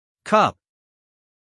cup-stop-us-male.mp3